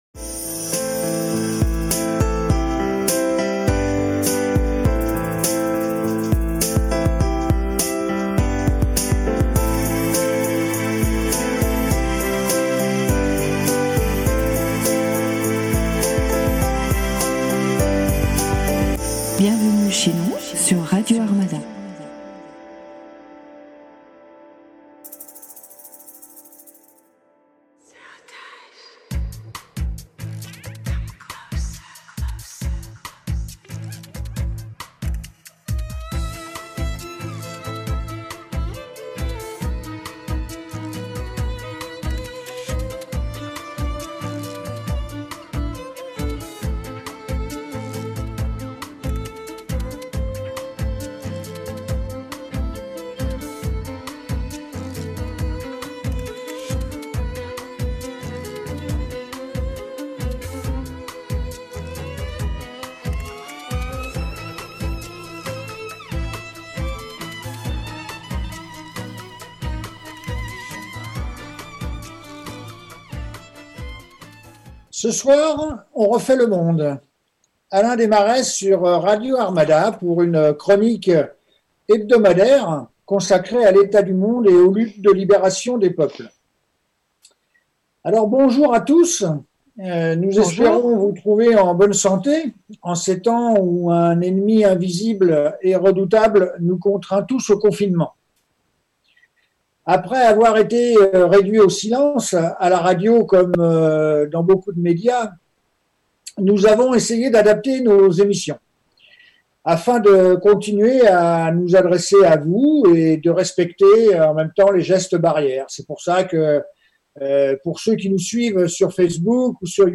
En fait à quoi servent-elle, la réponse, dans ce podcast réaliser dans les studios de la radio Arts-Mada.